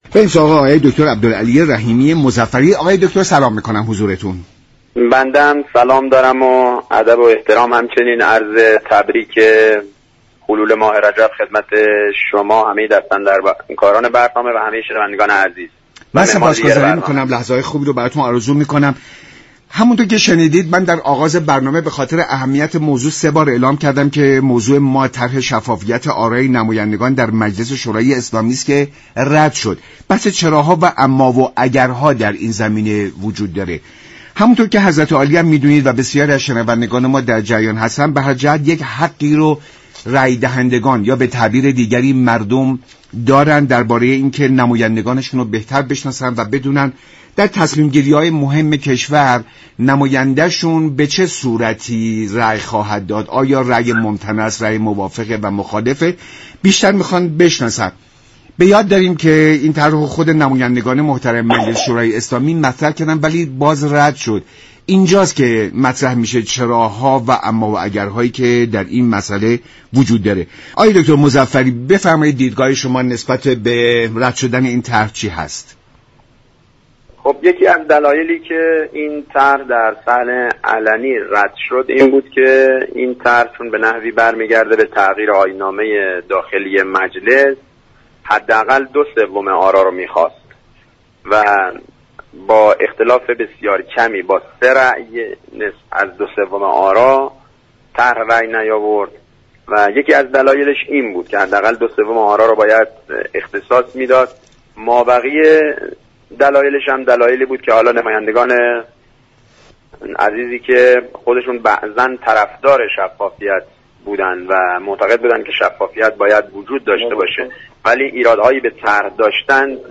به گزارش شبكه رادیویی ایران، عبدالعلی رحیمی مظفری عضو هیات رئیسه كمیسیون انرژی مجلس در برنامه ایران امروز به رد طرح شفافیت آرای نمایندگان اشاره كرد و گفت: این طرح كه برای تصویب به دو سوم آرا نیاز داشت با اختلاف سه رای به تصویب نرسید.